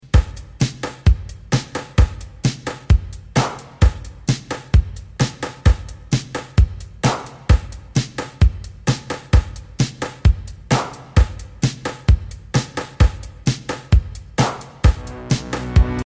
Since the LM-1’s sounds were actual recorded drum samples, it was way more realistic than its analog counterparts.
Linn LM-1